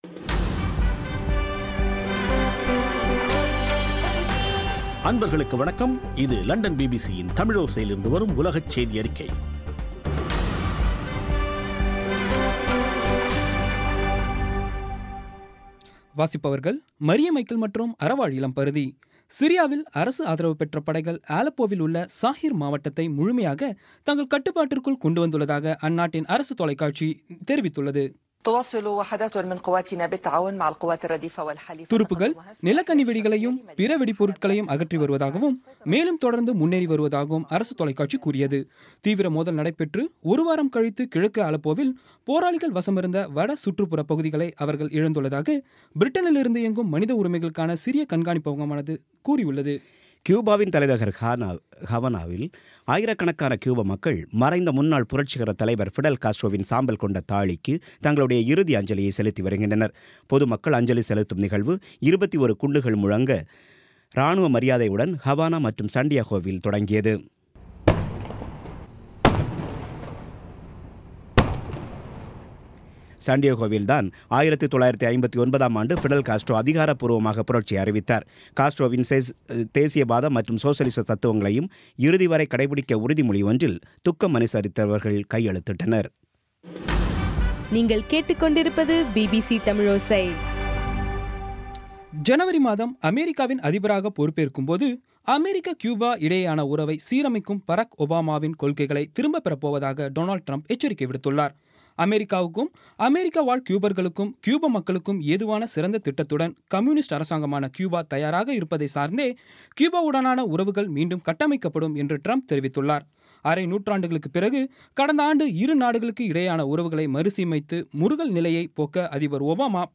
பி பி சி தமிழோசை செய்தியறிக்கை (28/11/16)